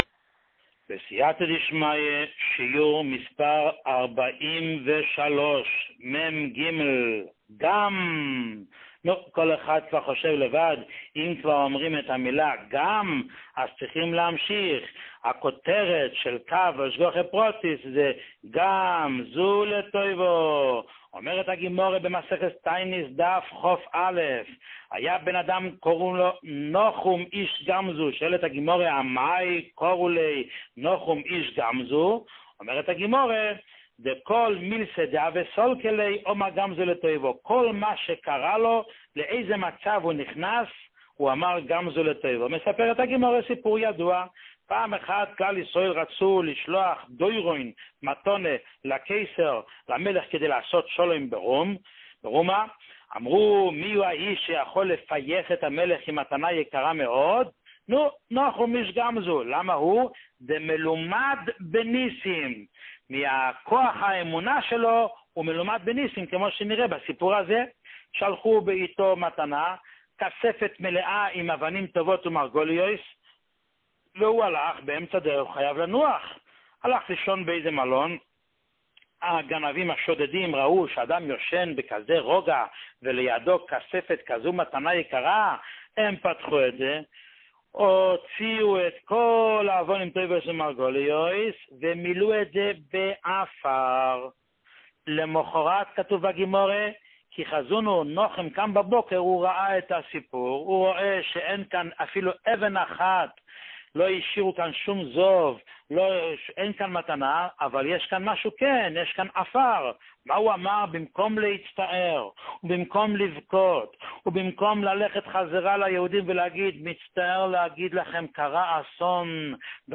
שיעור 43